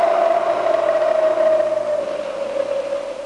Howling Wind Sound Effect
Download a high-quality howling wind sound effect.
howling-wind-1.mp3